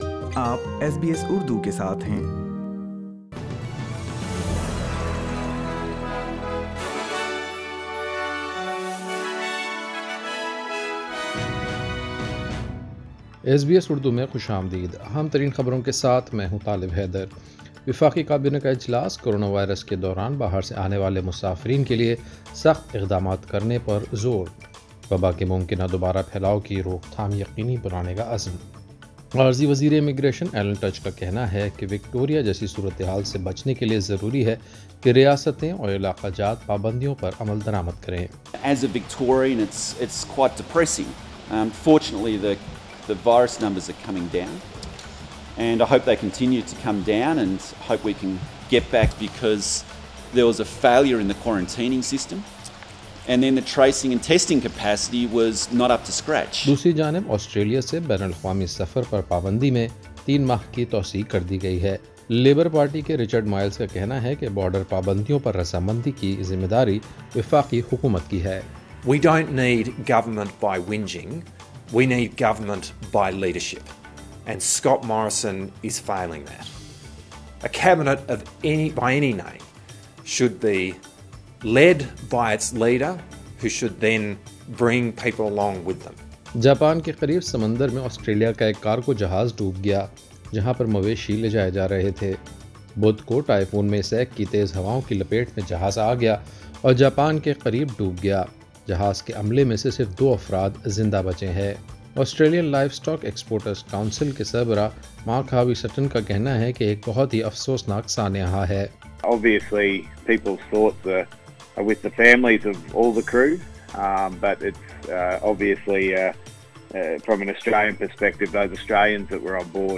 ایس بی ایس اردو خبریں 4 ستمبر2020